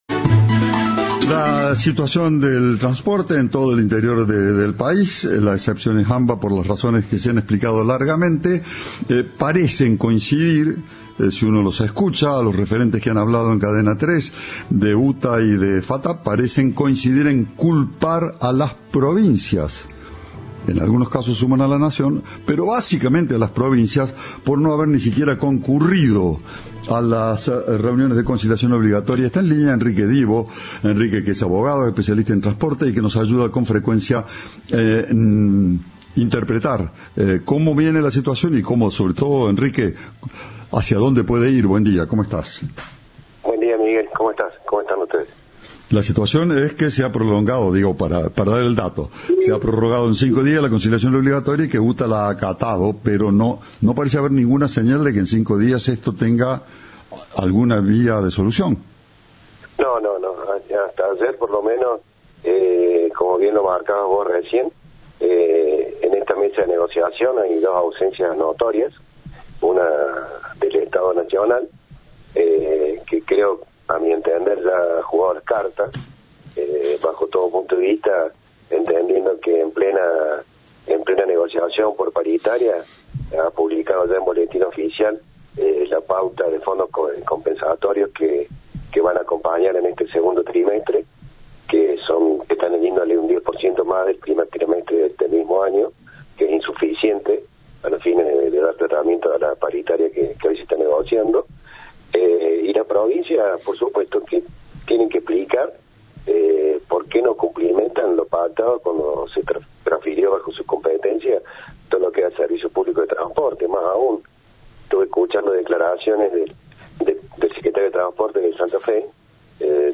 Entrevista de "Informados